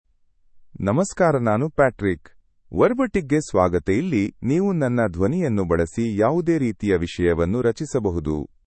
PatrickMale Kannada AI voice
Patrick is a male AI voice for Kannada (India).
Voice sample
Male
Patrick delivers clear pronunciation with authentic India Kannada intonation, making your content sound professionally produced.